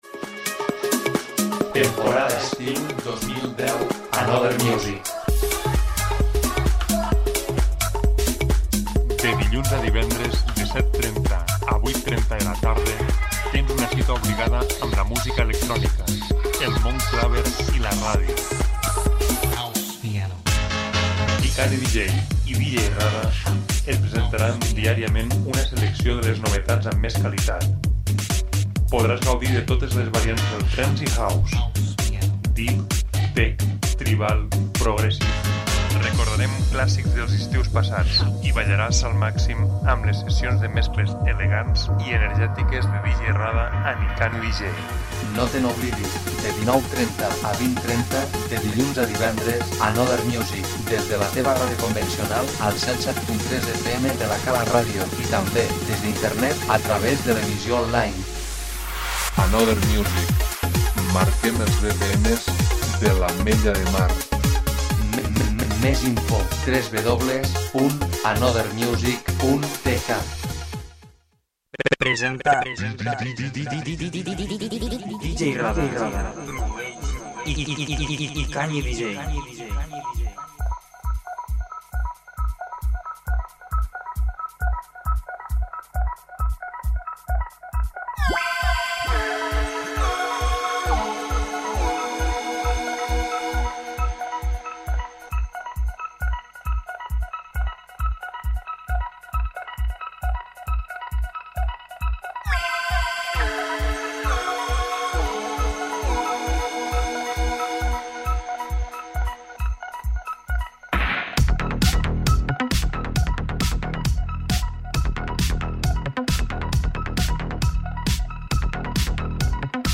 House & Trance